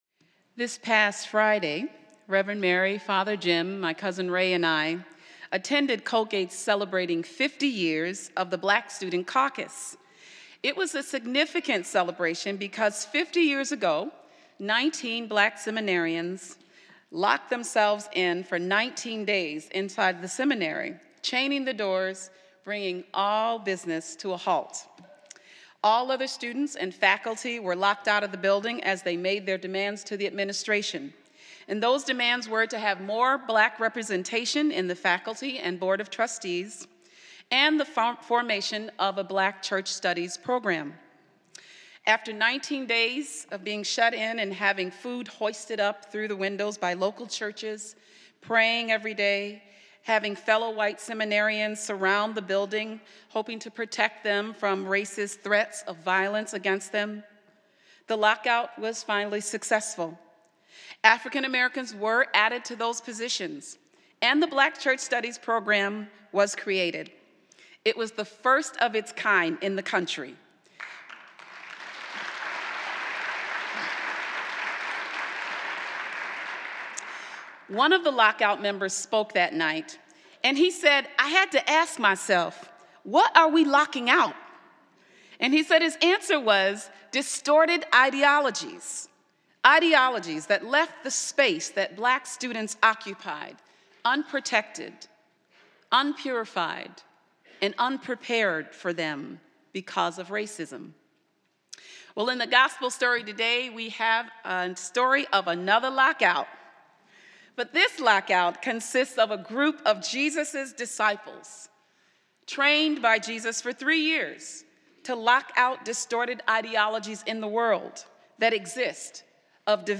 This is a Spiritus Christi Mass in Rochester, NY.